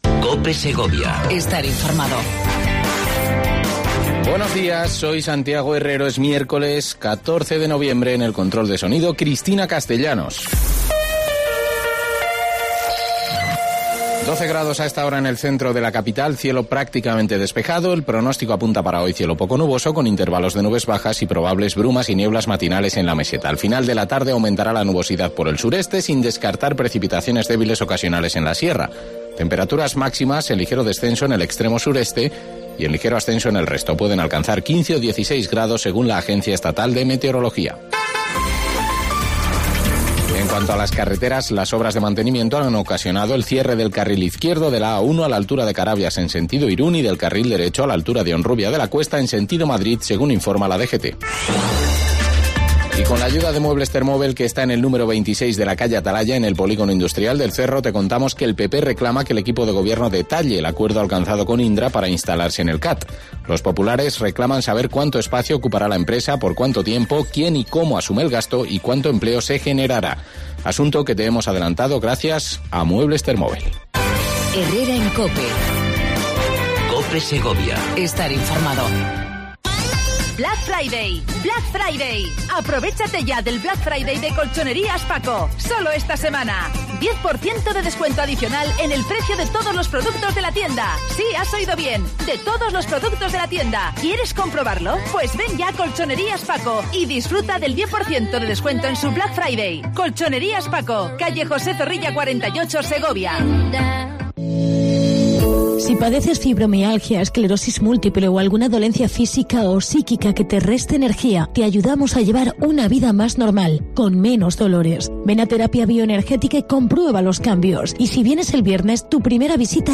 Entrevista mensual